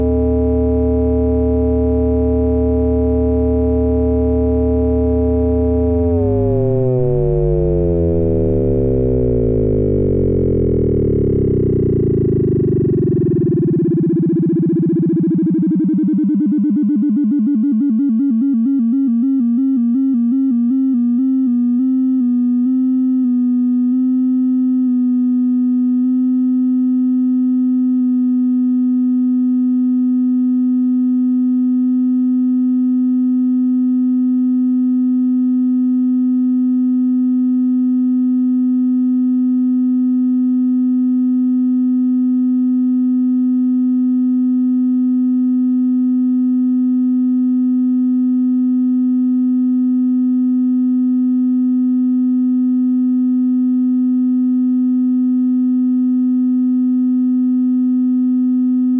Einer meiner besten Synths ist der Monotron.
Der klingt irgendwie immer toll, trotz, oder wegen?